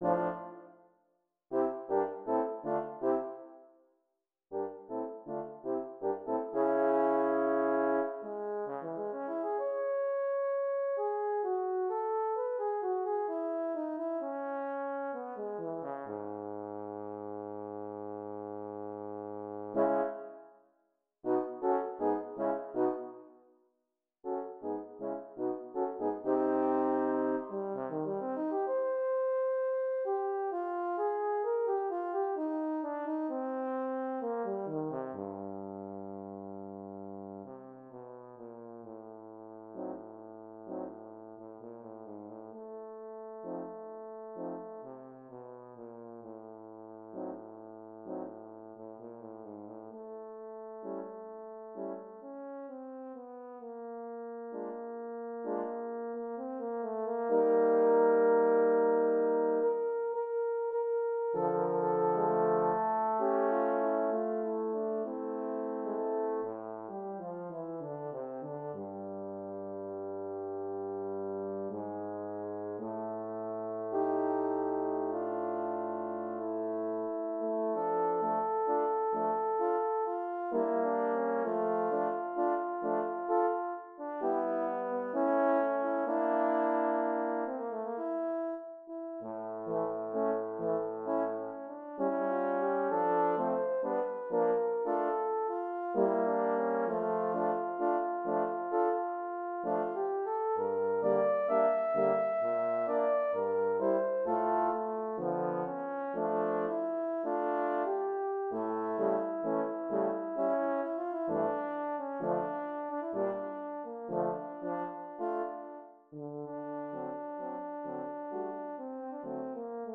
Intermezzo
Per quartetto di Corni